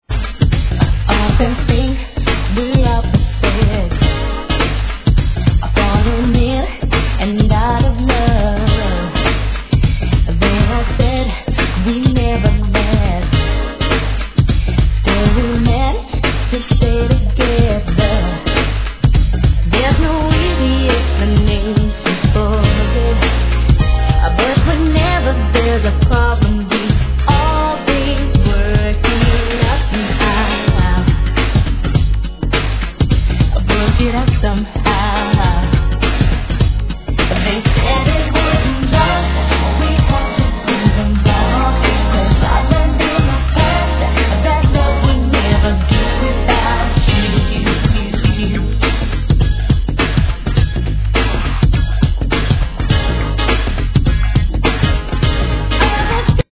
HIP HOP/R&B
イントロのコーラスからやられちゃってください♪ No. タイトル アーティスト 試聴 1.